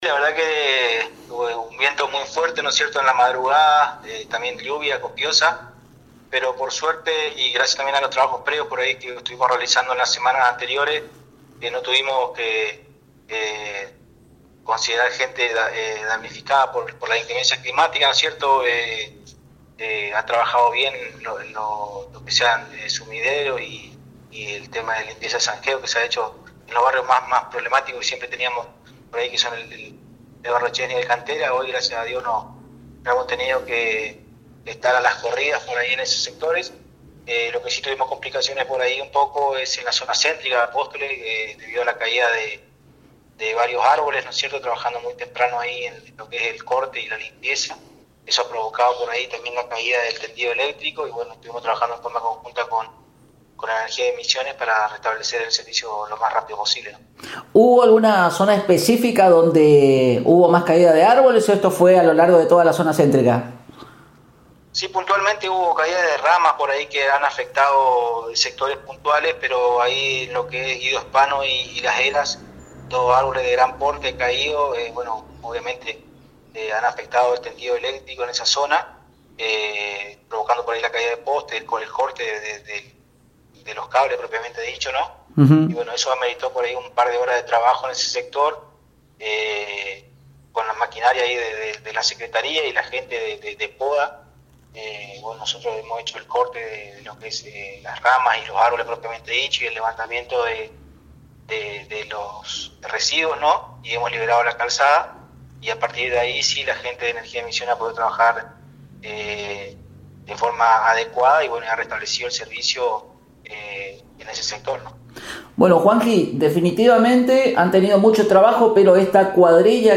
En comunicación telefónica con Radio Elemental